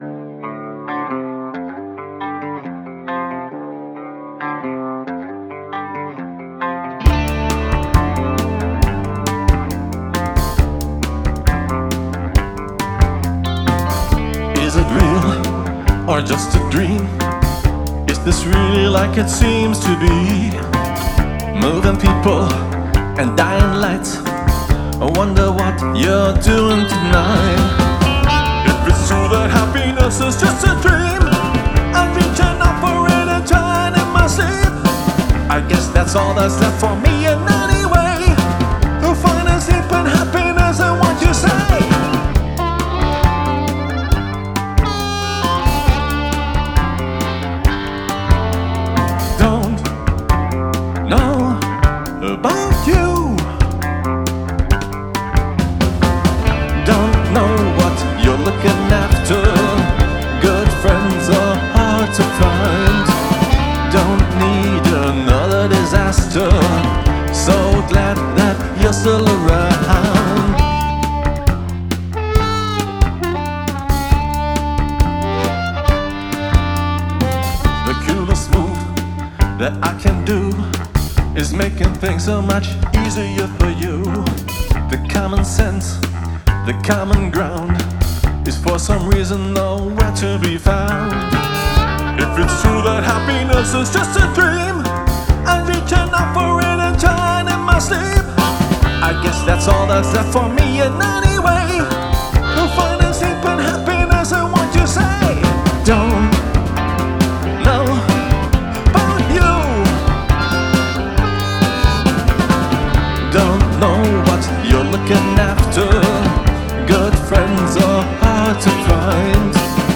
gitarre, bass, programming: icke
harp
gitarrensolo